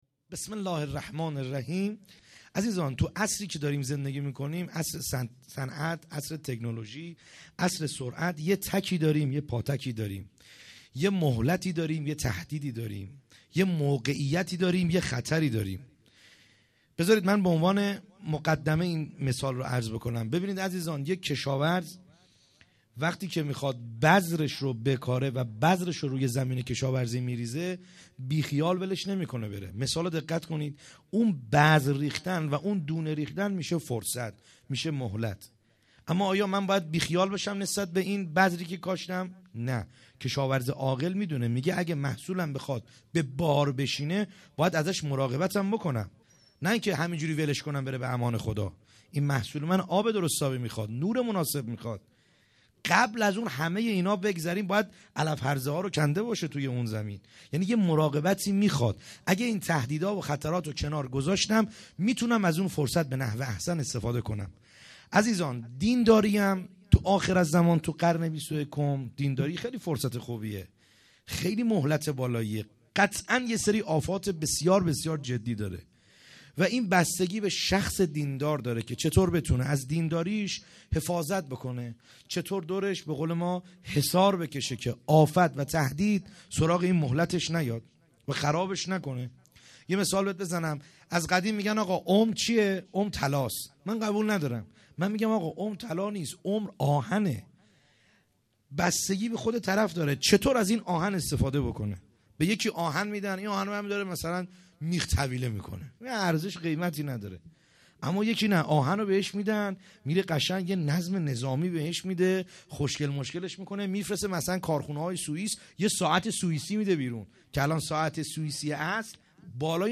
خیمه گاه - بیرق معظم محبین حضرت صاحب الزمان(عج) - سخنرانی | شب سوم